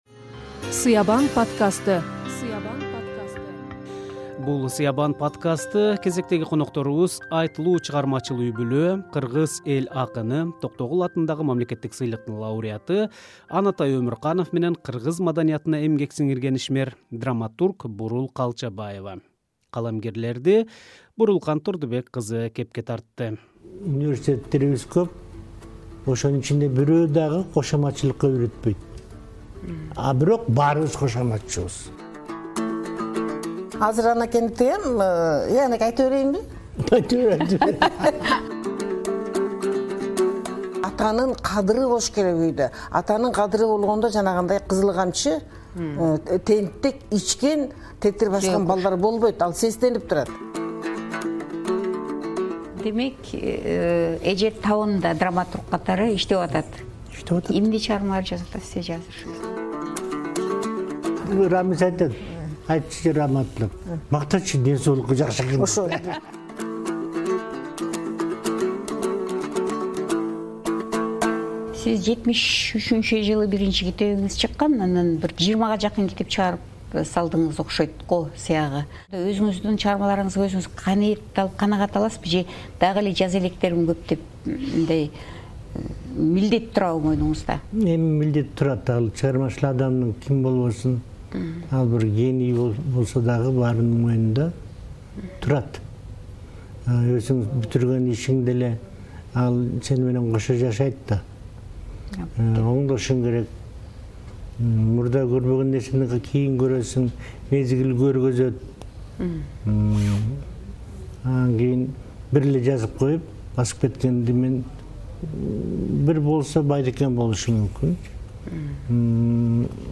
Чыгармачыл жубайлардын сукбаты